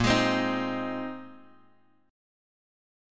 A#9sus4 Chord
Listen to A#9sus4 strummed